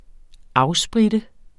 Udtale [ ˈɑw- ]